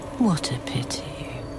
Download What a Pity Meme sound effect for free.